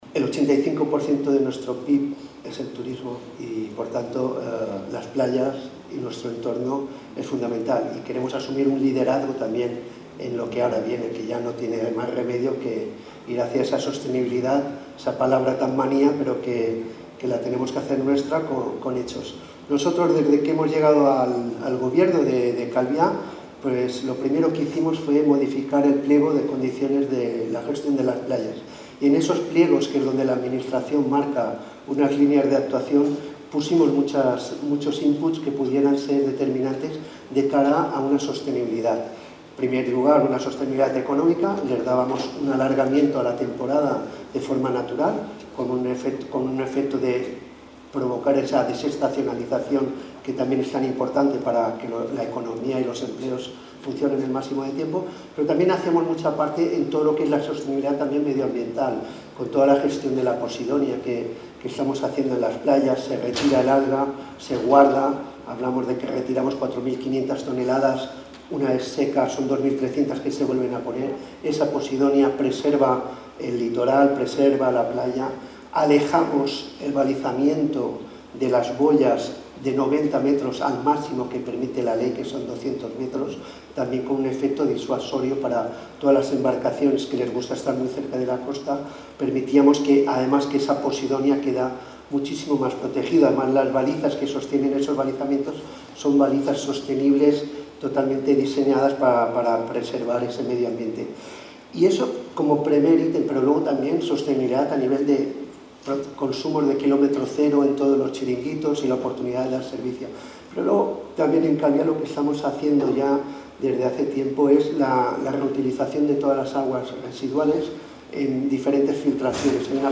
declaraciones-del-alcalde.mp3